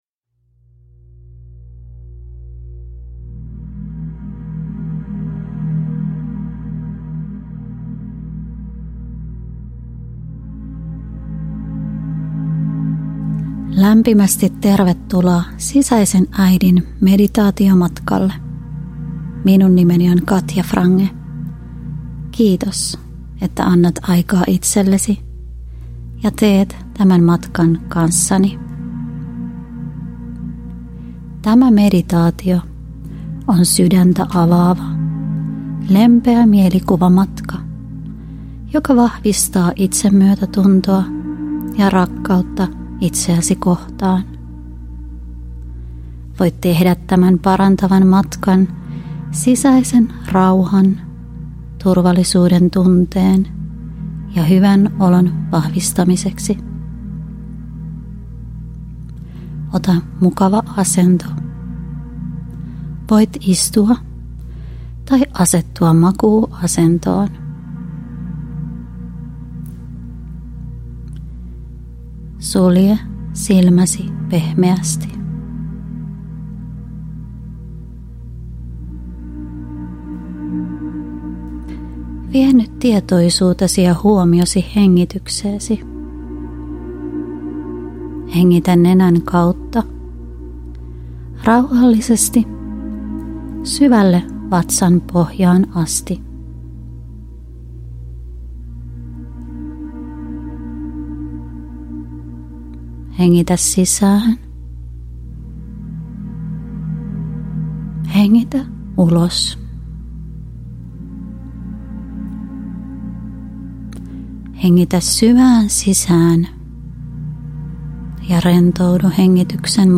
Sisäinen äiti-meditaatio – Ljudbok – Laddas ner